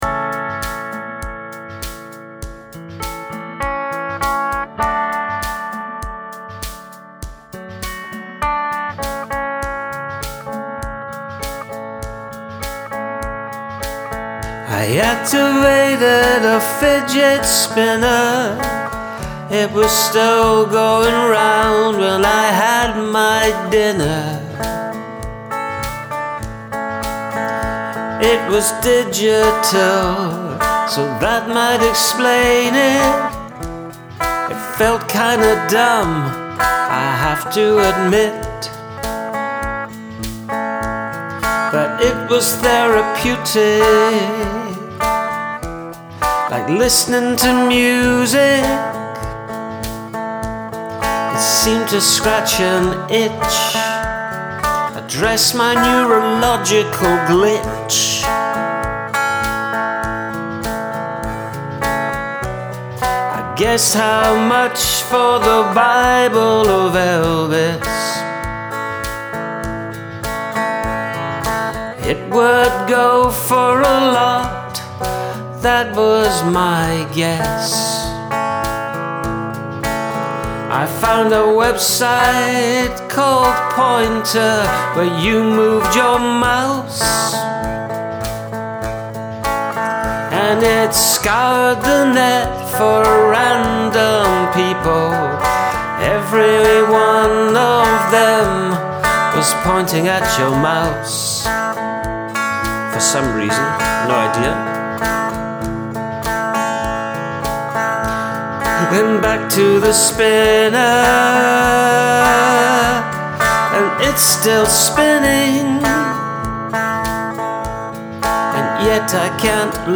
The amused spoken aside in this one was a nice twist.
Really cool song - i like the guitar sound and the idea of searching down the rabbit hole of weird internet pages